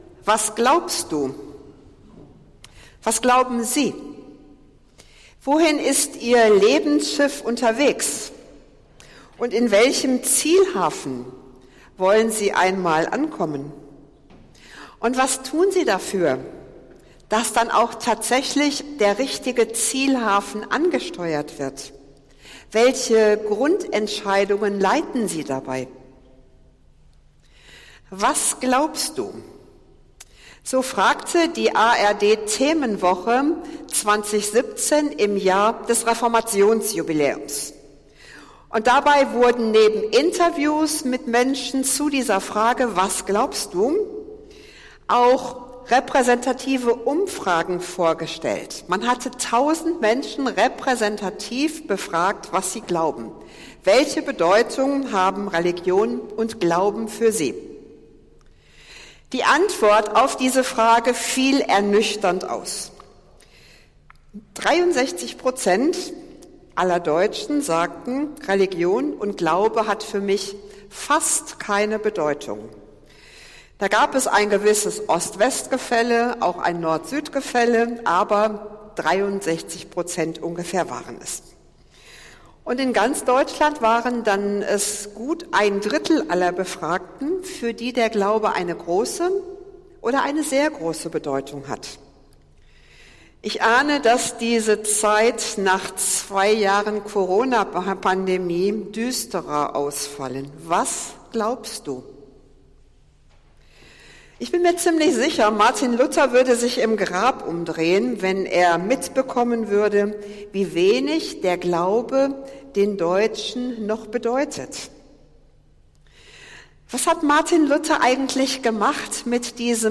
Predigt des Gottesdienstes aus der Zionskirche zum Reformationstag am 31. Oktober 2022